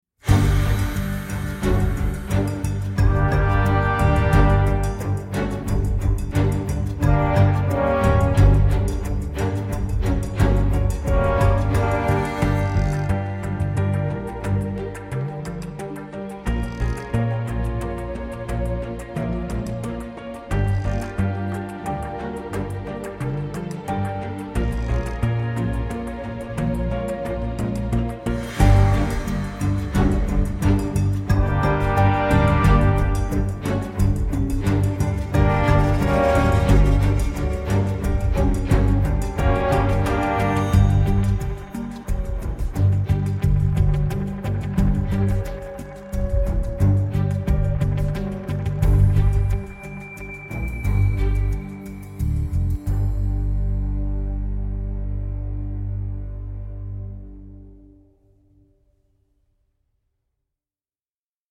a delicate and quirky score